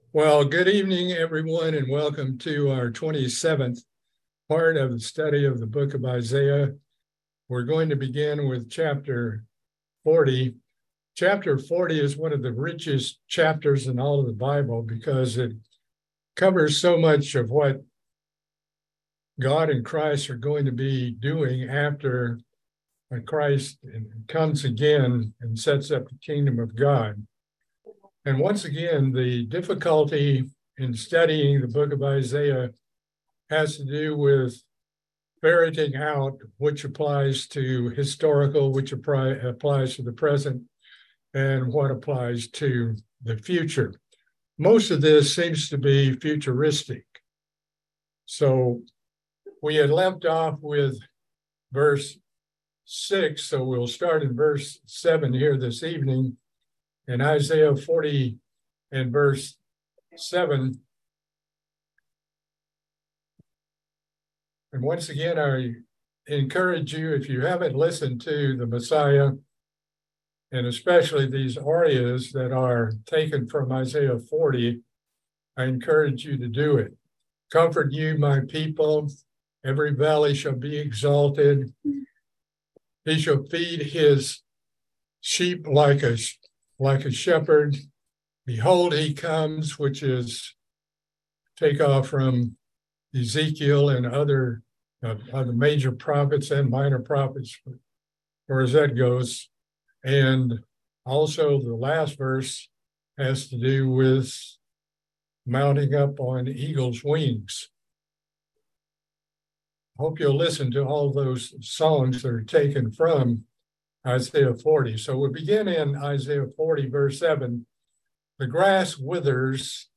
Book of Isaiah Bible Study - Part 27